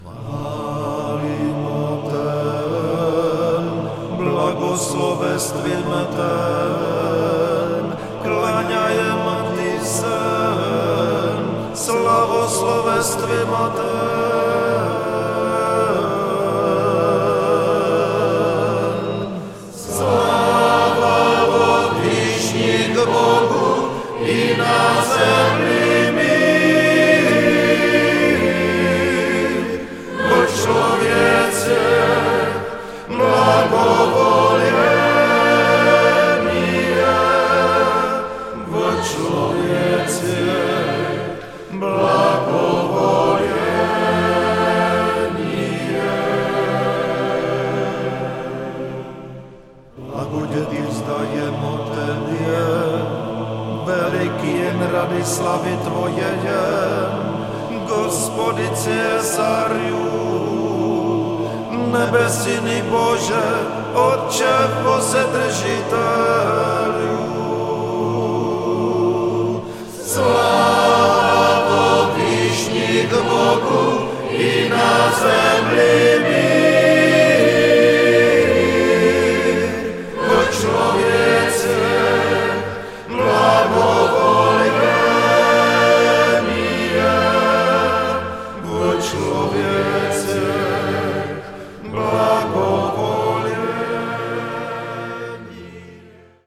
staroslověnský liturgický text